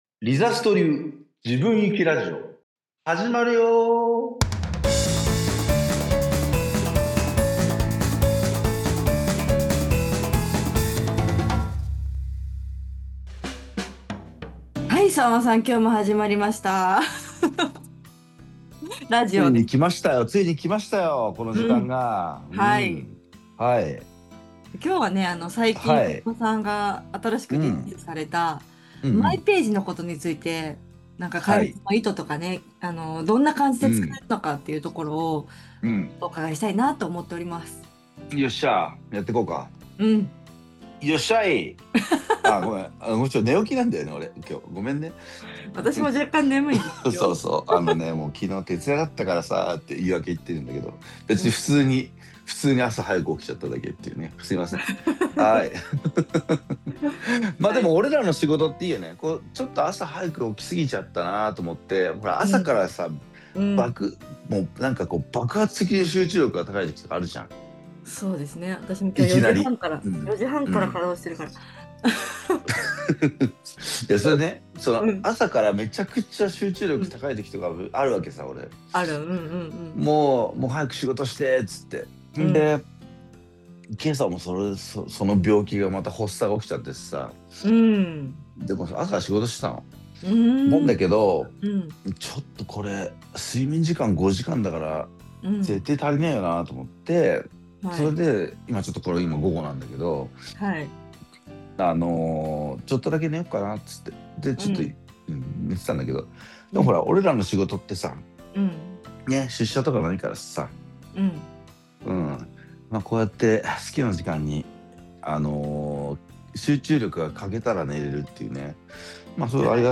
リザストに新たに「マイページ」ができました！今回のラジオでは、この新機能について開発にあたっての想いも含めてお話をお伺いしました。